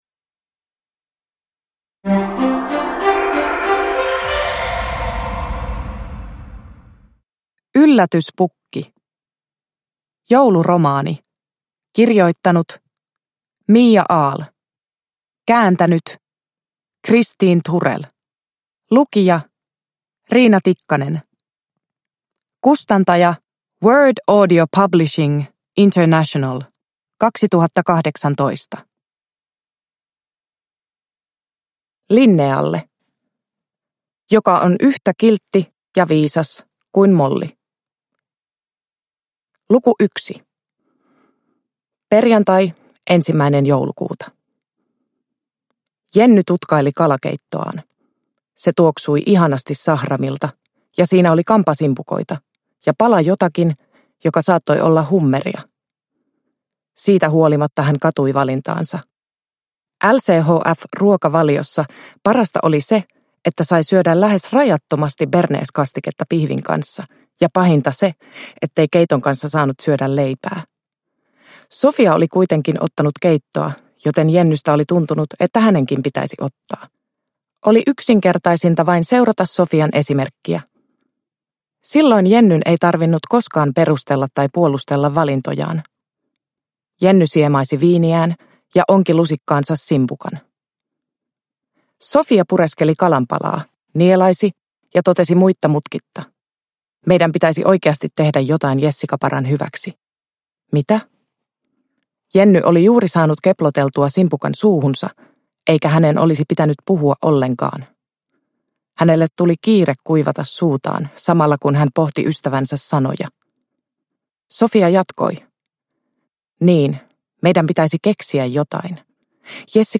Yllätyspukki – Ljudbok